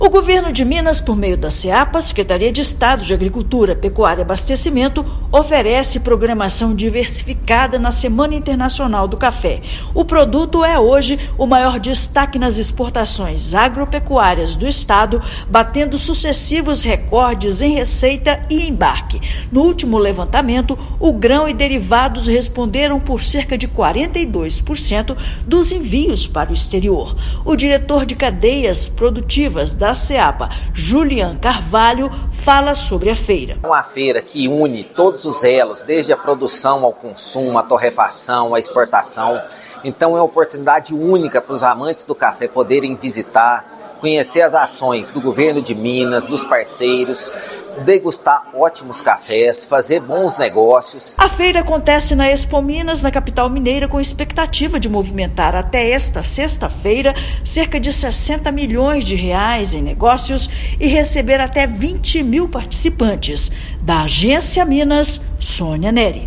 Seapa e vinculadas terão opções para produtores e visitantes na 12ª edição do evento, na capital mineira. Ouça matéria de rádio.